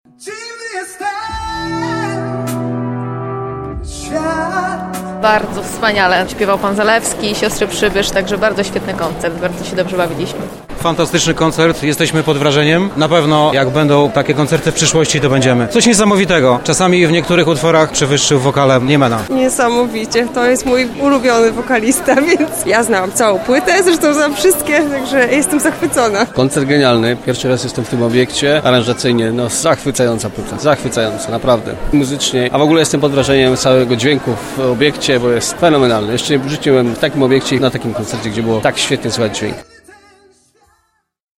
Nasza reporterka rozmawiała z widzami o ich wrażeniach z koncertu.